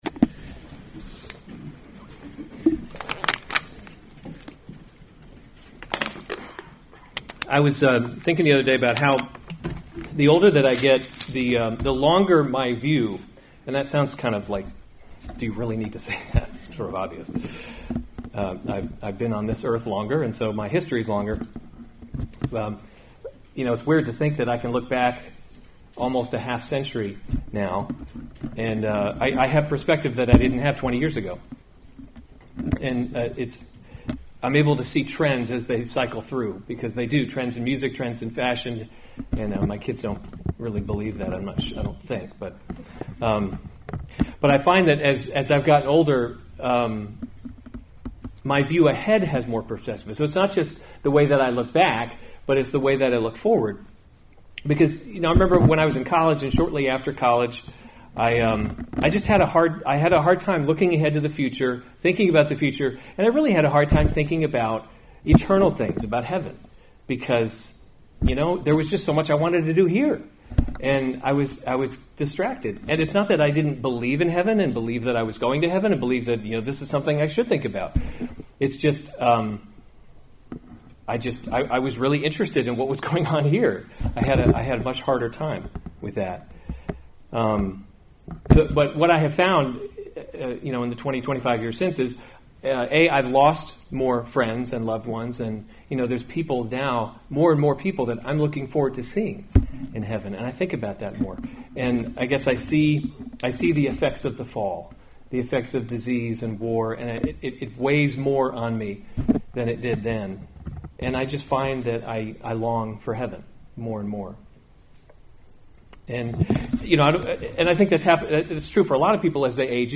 June 3, 2017 1 Timothy – Leading by Example series Weekly Sunday Service Save/Download this sermon 1 Timothy 6:11-16; 20-21 Other sermons from 1 Timothy Fight the Good Fight of […]